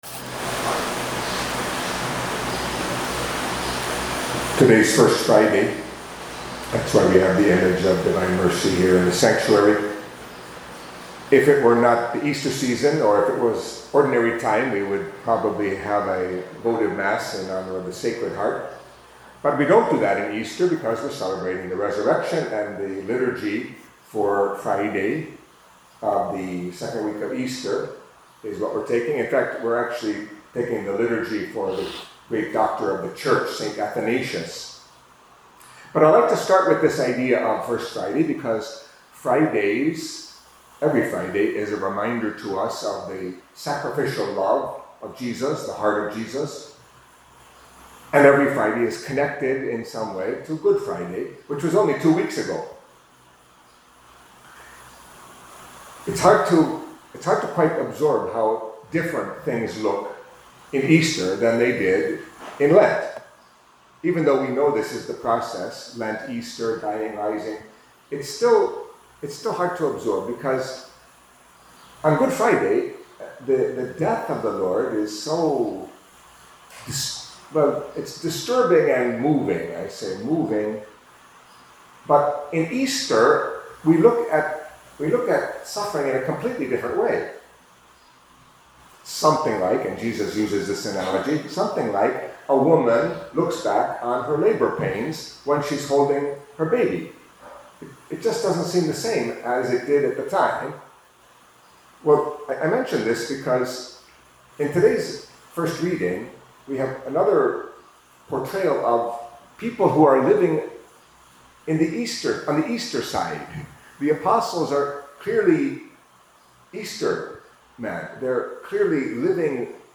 Catholic Mass homily for Friday of the Second Week of Easter